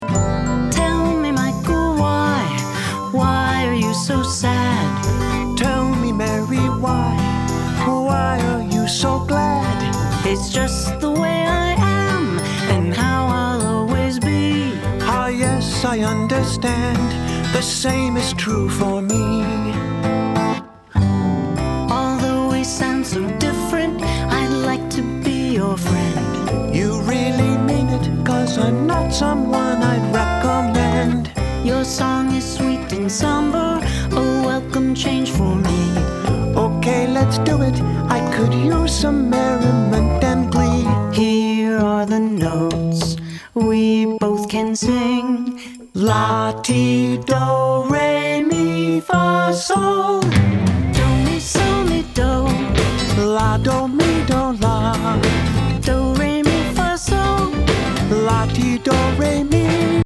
Independent Music Awards, Best Childrens Song
Listen to a sample of this song